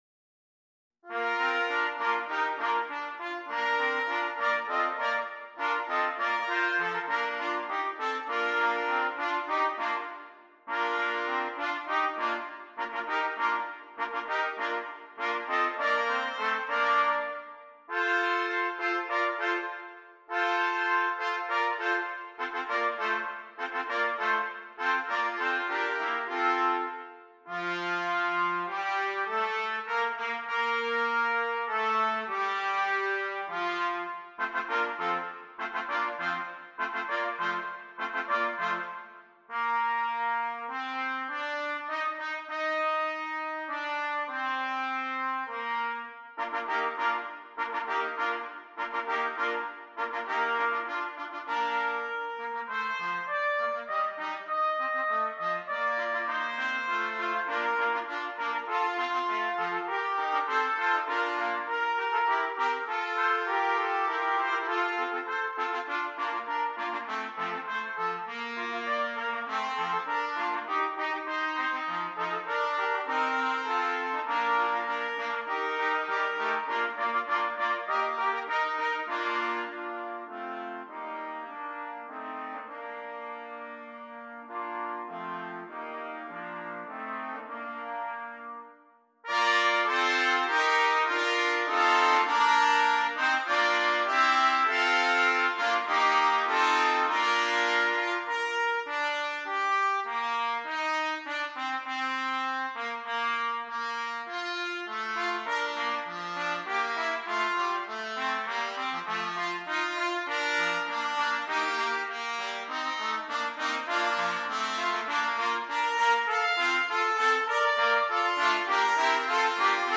4 Trumpets